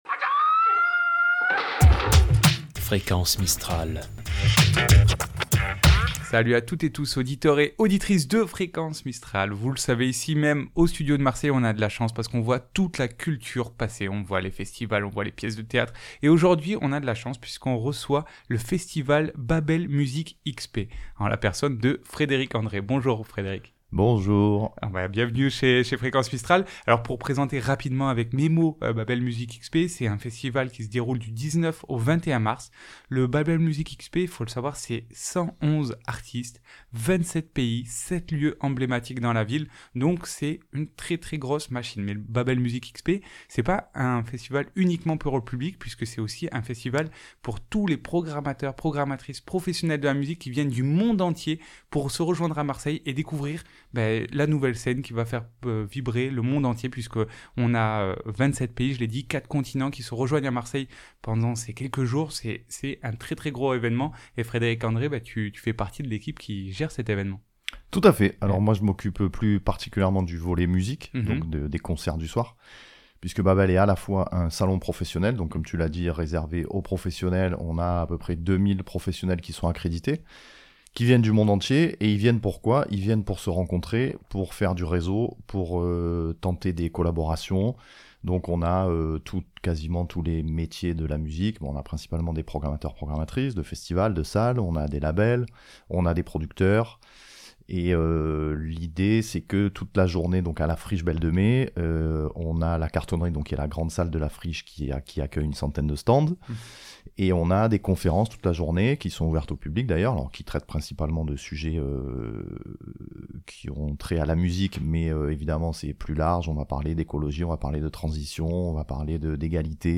Itw Babel Music XP .mp3 (19.26 Mo)